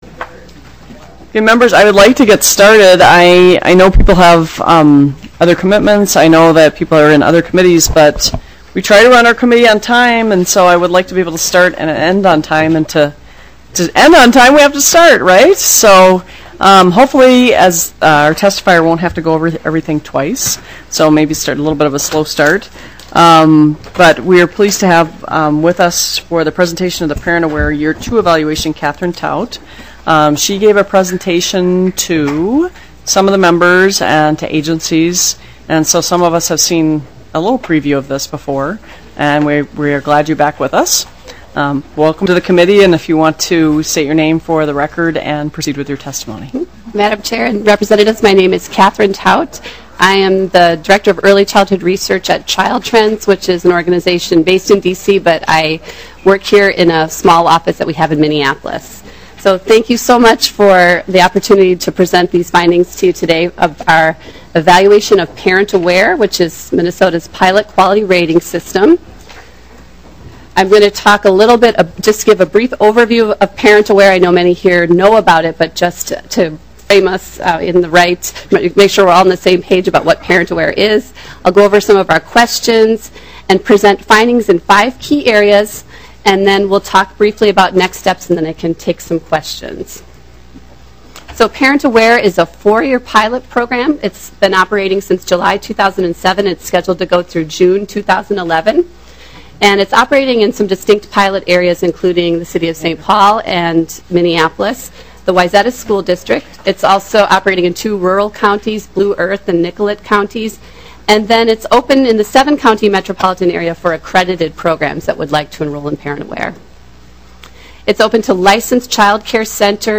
Early Childhood Finance and Policy Division TWENTY-EIGHTH MEETING - Minnesota House of Representatives